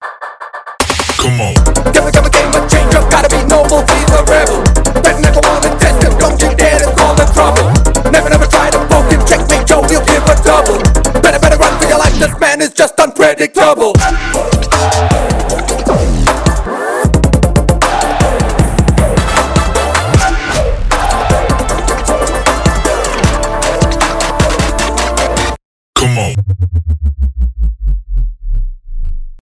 future bass , мощные басы , громкие